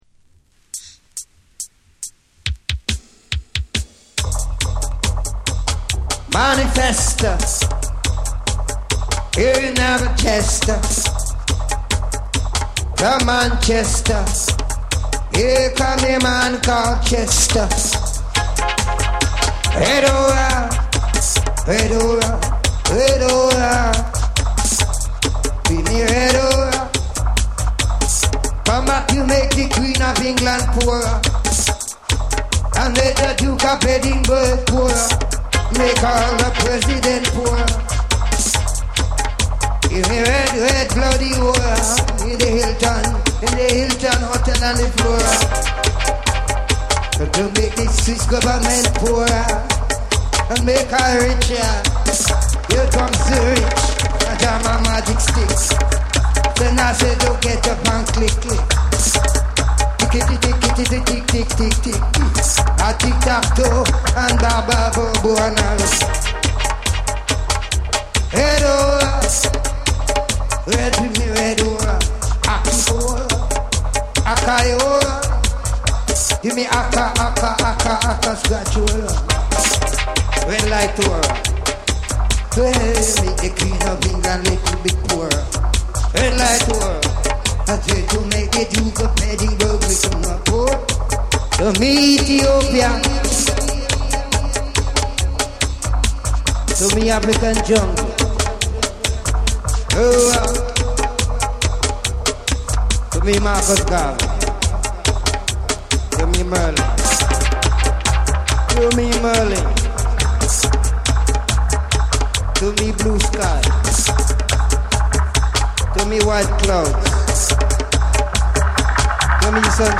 JUNGLE & DRUM'N BASS / REGGAE & DUB